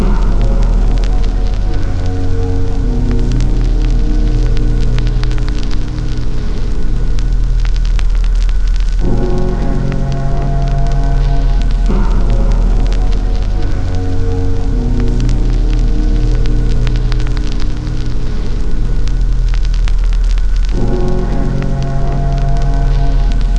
hospital.wav